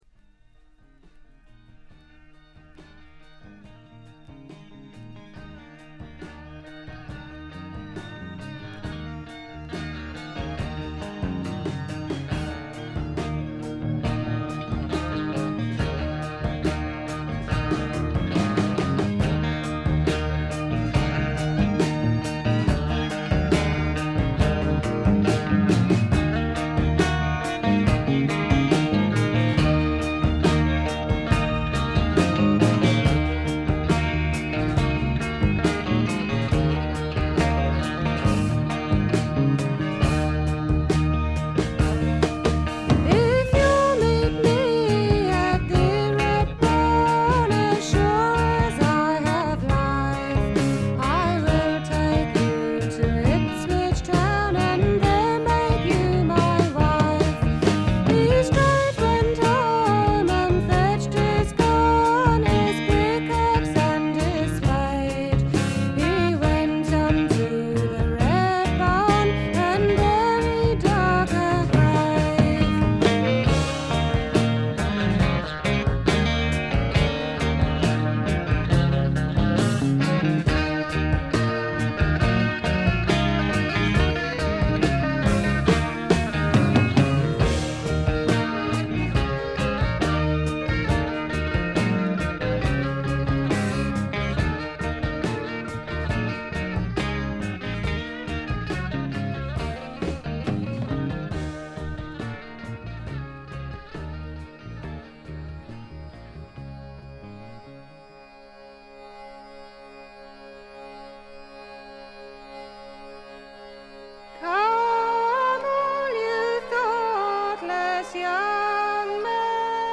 エレクトリック・トラッド最高峰の一枚。
試聴曲は現品からの取り込み音源です。